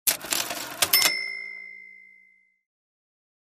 Звук кассового аппарата